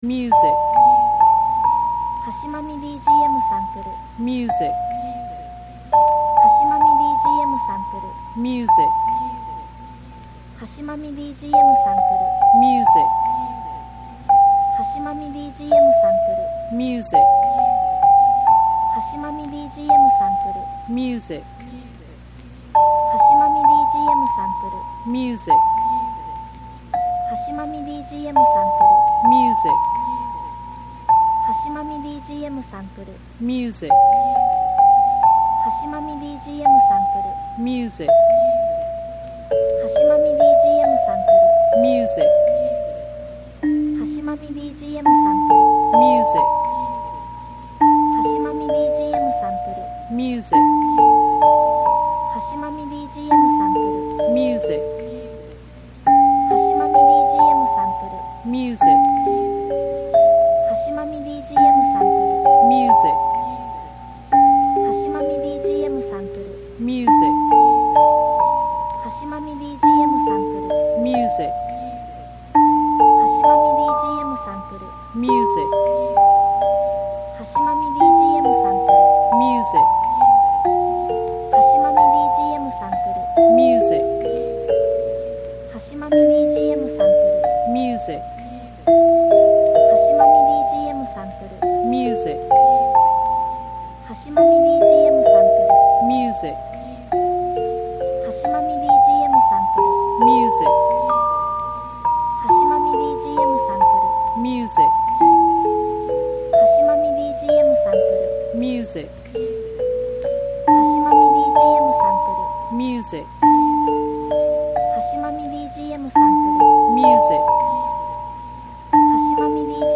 オルゴールのみの暗く寂しいミステリアスな曲